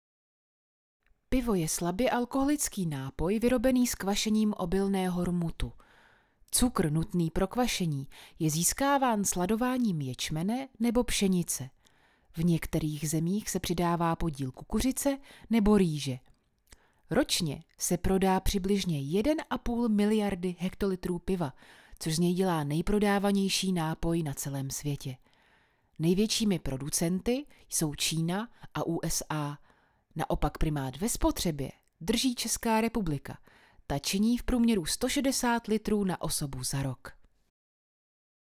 Umím: Voiceover
Ženský hlas - Voice over/ Dabing